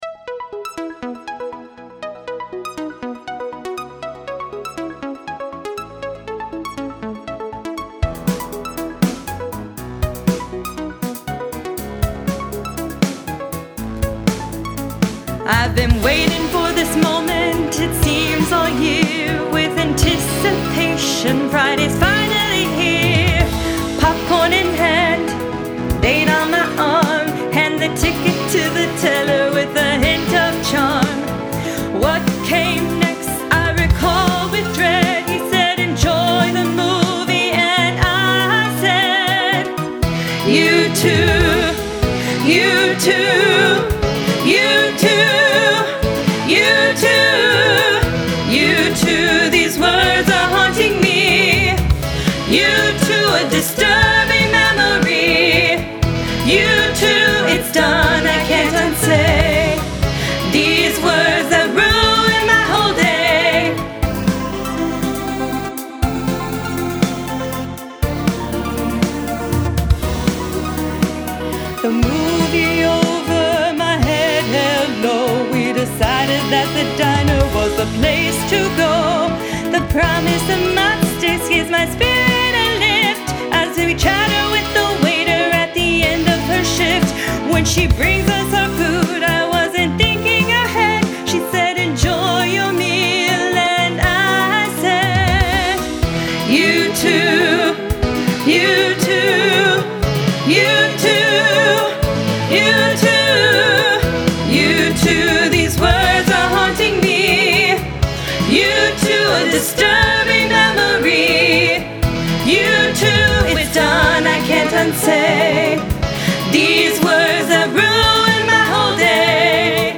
Contrafact chorus or contrafact verse(s)